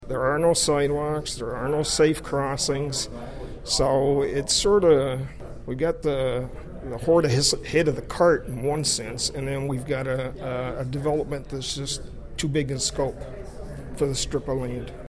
Councillor Bill Sandison tells Quinte News he has concerns about such a development so close to Belleville’s first traffic roundabout.